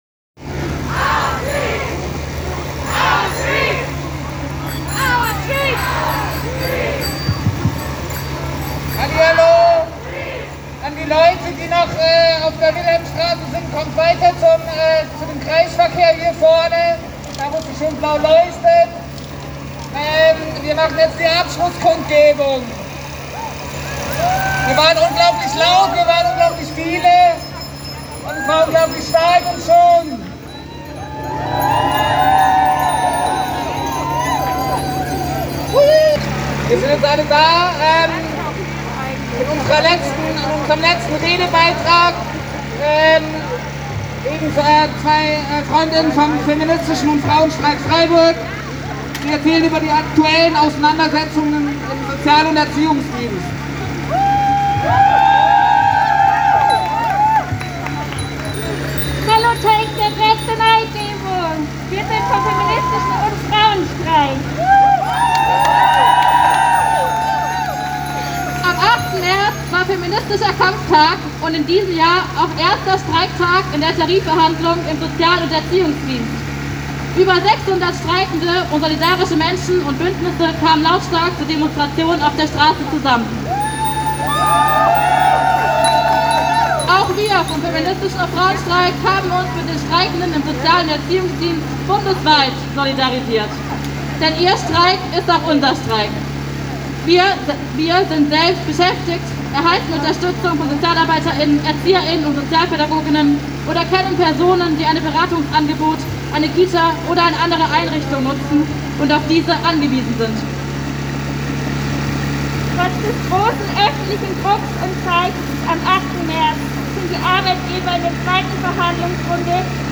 Vor dem Martinstor, am Freiburger Stadtgarten, am Lederleplatz im Stühlinger sowie nahe der Wilhelmstraße wurden Reden gehalten:
Abschlusskundgebung WilhelmstrasseKreisel.mp3